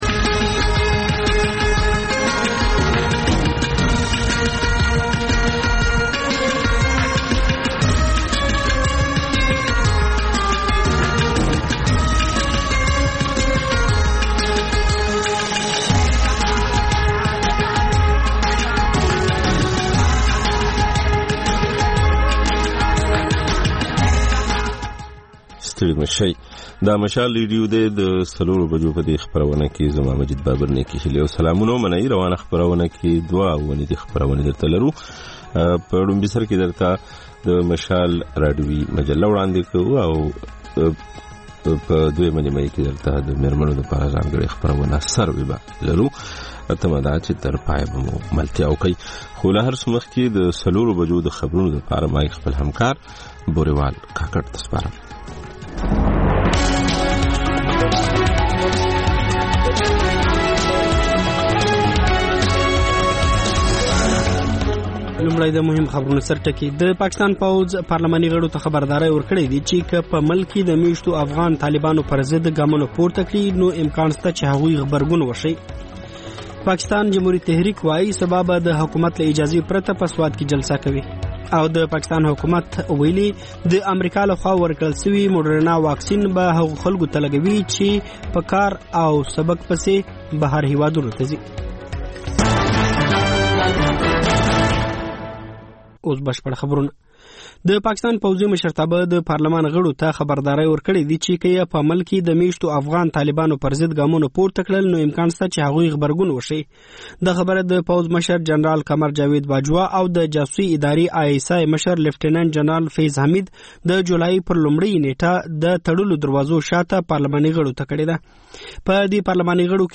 د مشال راډیو مازیګرنۍ خپرونه. د خپرونې پیل له خبرونو کېږي، بیا ورپسې رپورټونه خپرېږي. ورسره اوونیزه خپرونه/خپرونې هم خپرېږي.